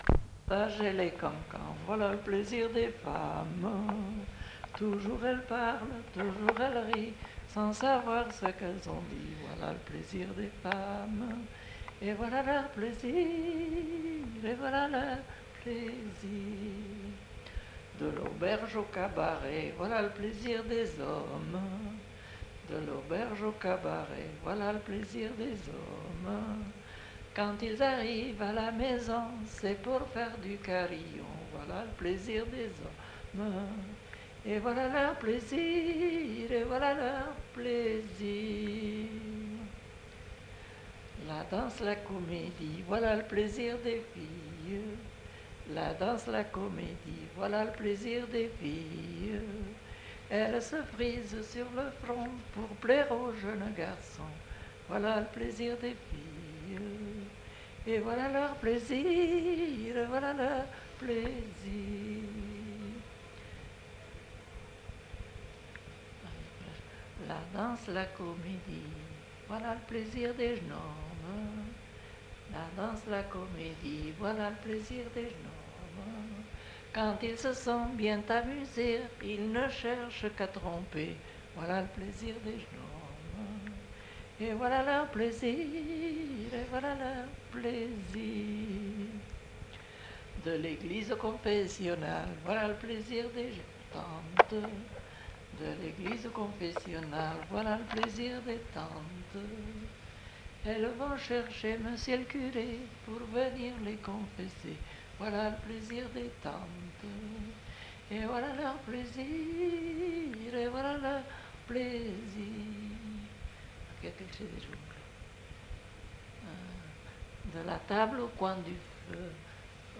Aire culturelle : Viadène
Lieu : Saint-Amans-des-Cots
Genre : chant
Effectif : 1
Type de voix : voix de femme
Production du son : chanté